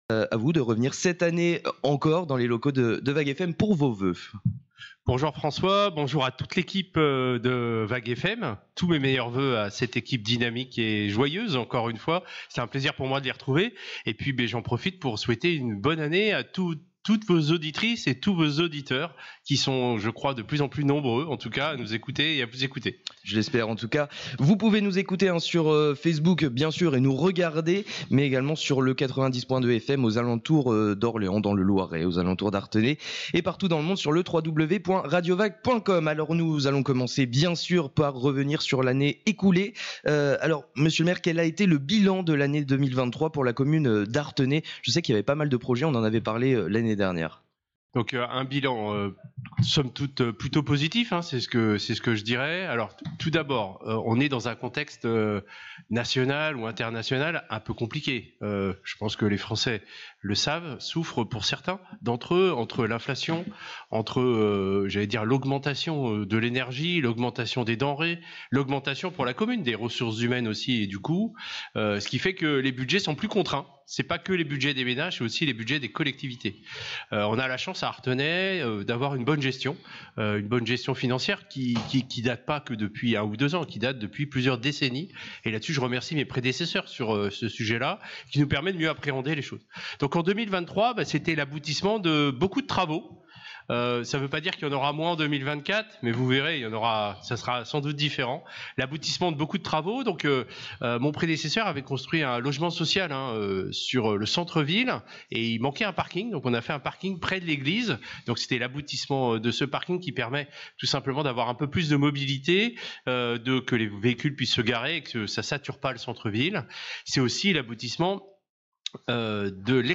Interview du maire d'Artenay à l'occasion des vœux 2024
Interview de David Jacquet à l'occasion des voeux 2024 aux administrés.